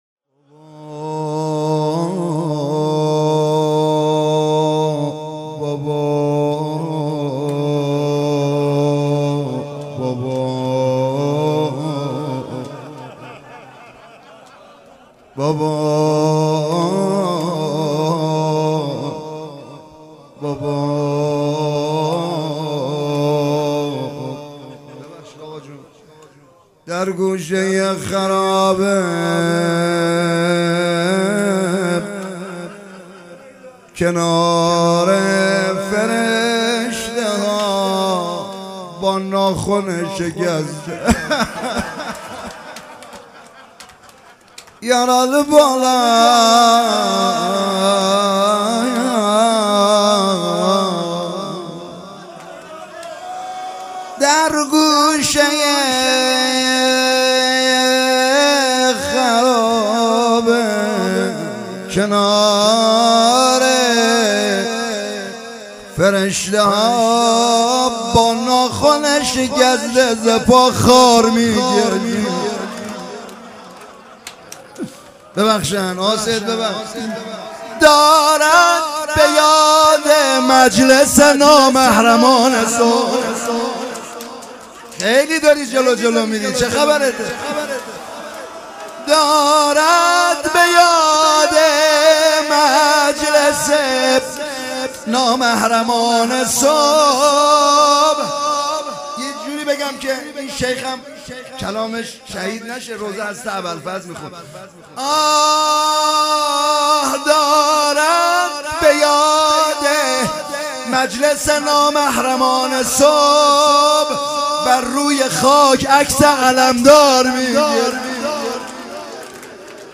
مراسم مناجات شب بیست و دوم ماه رمضان
روضه